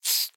Minecraft Version Minecraft Version 1.21.5 Latest Release | Latest Snapshot 1.21.5 / assets / minecraft / sounds / mob / silverfish / say1.ogg Compare With Compare With Latest Release | Latest Snapshot